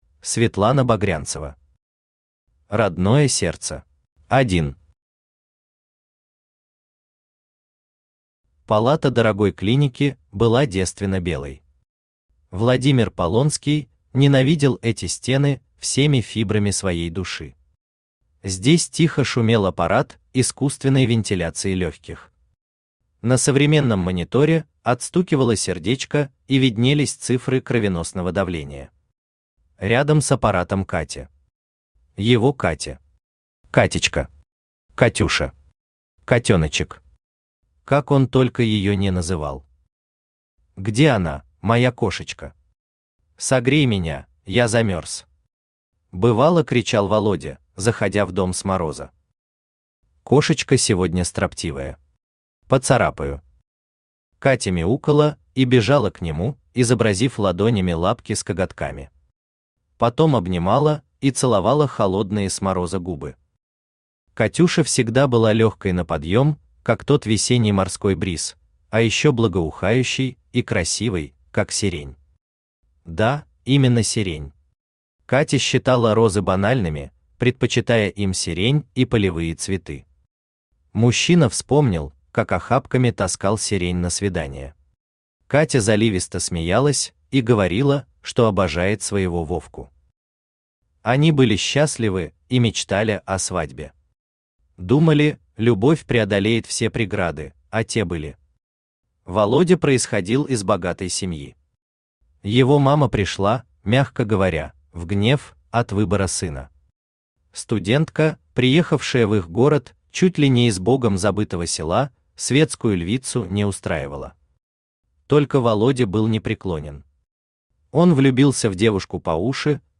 Аудиокнига Родное сердце | Библиотека аудиокниг
Aудиокнига Родное сердце Автор Светлана Багрянцева Читает аудиокнигу Авточтец ЛитРес.